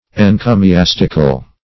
Search Result for " encomiastical" : The Collaborative International Dictionary of English v.0.48: Encomiastic \En*co`mi*as"tic\, Encomiastical \En*co`mi*as"tic*al\, a. [Gr.